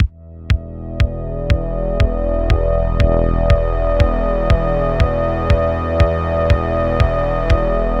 Create a signal that is going to modulate the sidechain input like a kick drum. I used a drum rack preset from the Ableton library and draw a basic 4/4 kick pattern.
I used an Analog pad preset and draw a long E1 MIDI note.
loop_no-compression.mp3